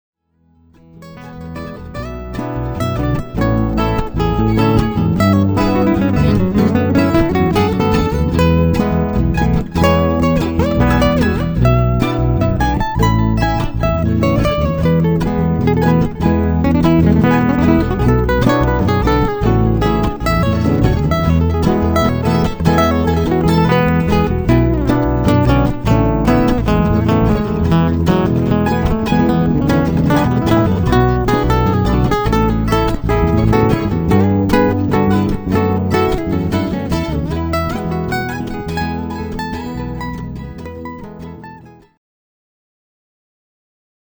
and contemporary jazz.
His nylon string guitar sings over original
hypnotic grooves, complimenting many venues